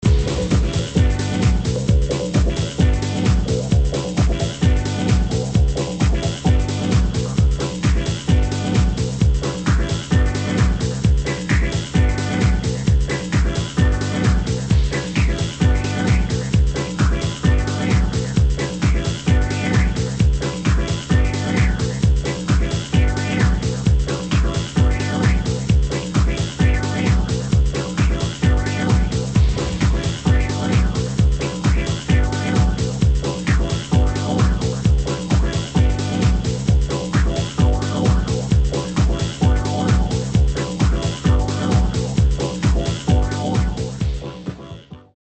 [ HOUSE | DISCO | BREAKBEATS ]